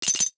NOTIFICATION_Glass_03_mono.wav